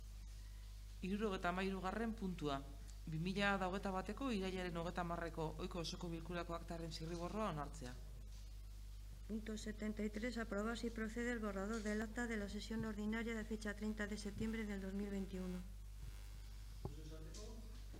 Sesión Extraordinaria del pleno del Ayuntamiento, celebrada en el Salón de Actos de la Casa Consistorial el día 28 de octubre de 2021, a las 19:00h.
Eneritz Azpitarte Aranzabal, Concejala - EH BILDU (00:08:21)
Juan Mari Uriarte Osa, Alcalde en funciones - EAJ-PNV (00:20:04)